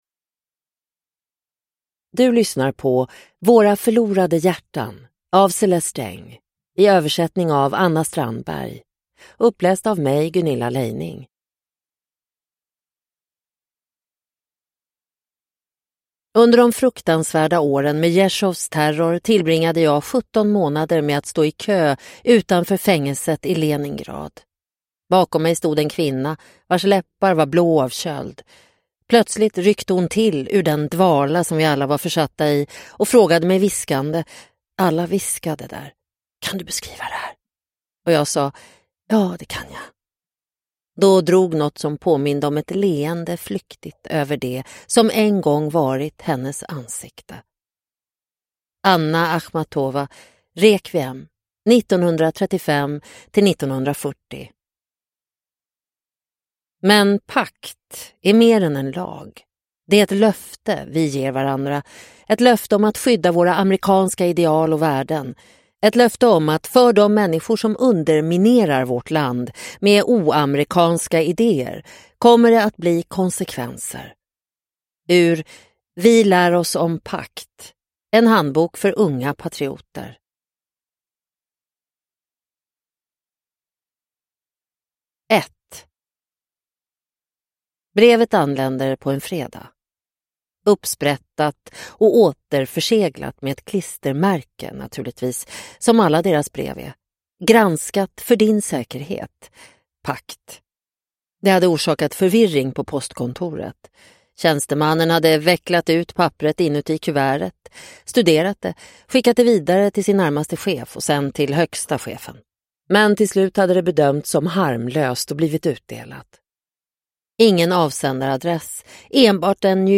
Våra förlorade hjärtan – Ljudbok – Laddas ner